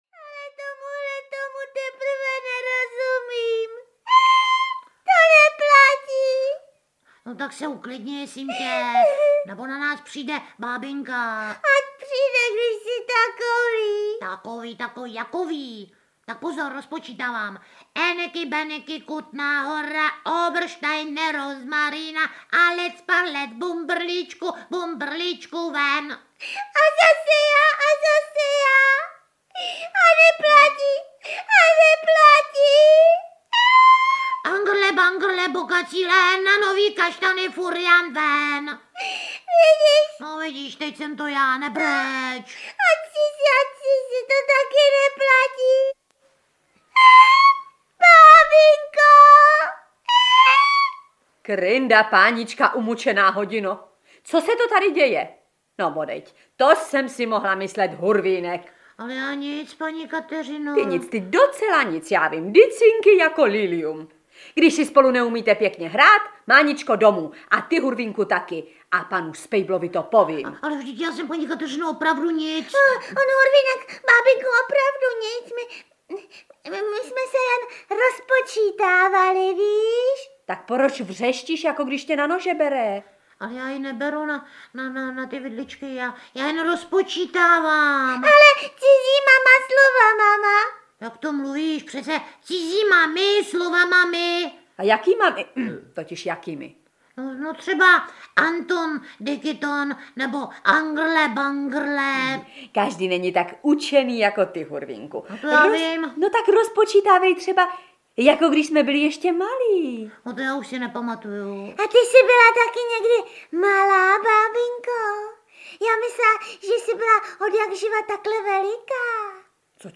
Audiokniha Hurvínkovy příhody 5, v podaní Miloše Kirschnera, Heleny Štáchové, Josefa Skupy.
Ukázka z knihy
• InterpretMiloš Kirschner, Helena Štáchová, Josef Skupa